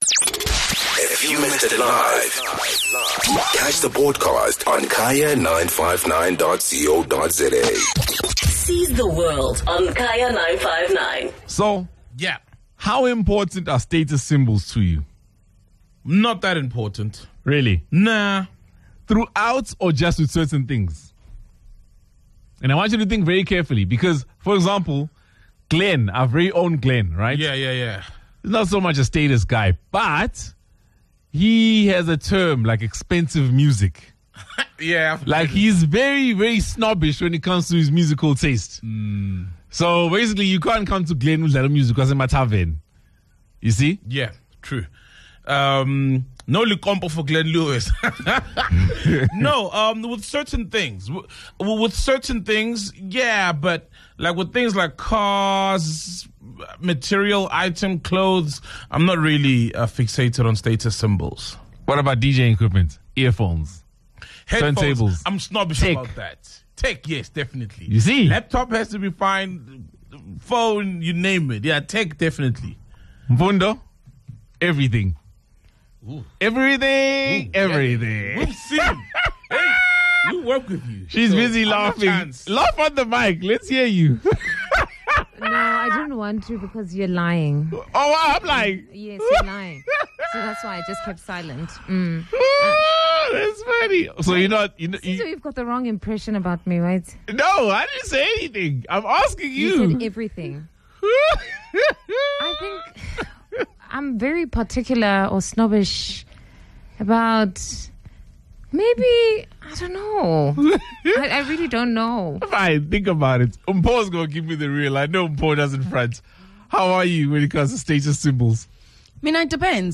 The team held a discussion on status symbols.